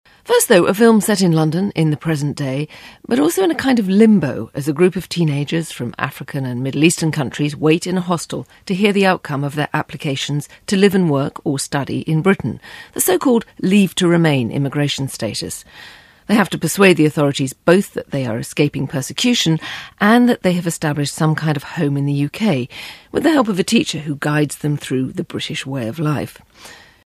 【英音模仿秀】居留许可 听力文件下载—在线英语听力室